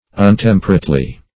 untemperately - definition of untemperately - synonyms, pronunciation, spelling from Free Dictionary Search Result for " untemperately" : The Collaborative International Dictionary of English v.0.48: Untemperately \Un*tem"per*ate*ly\, adv.
untemperately.mp3